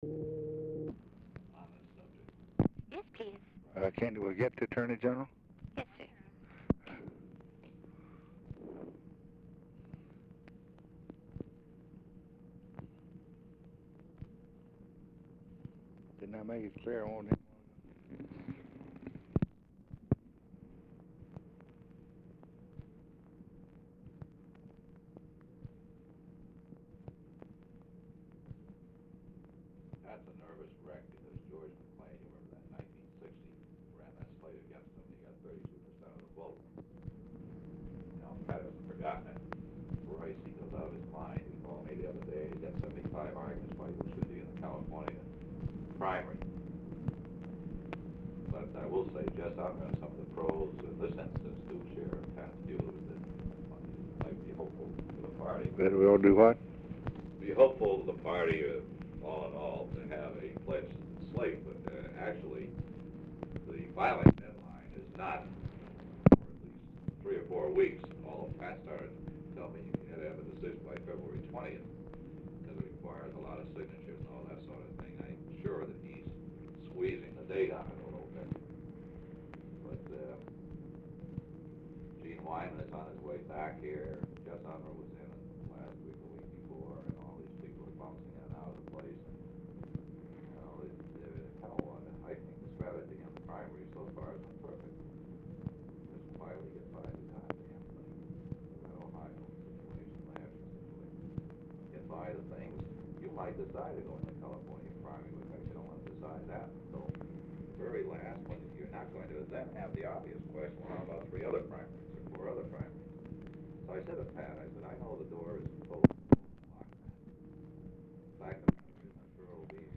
Oval Office or unknown location
OFFICE CONVERSATION PRECEDES CALL; LBJ IS MEETING WITH LARRY O'BRIEN AT TIME OF CALL
Telephone conversation
Dictation belt